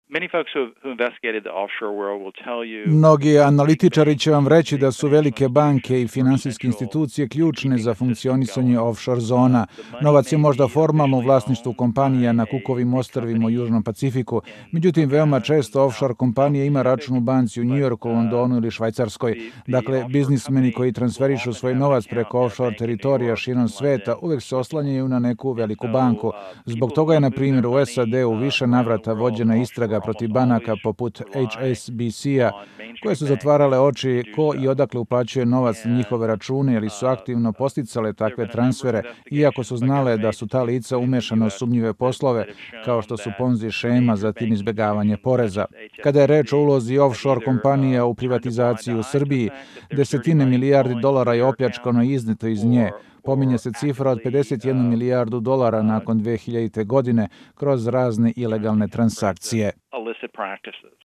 Deo intervjua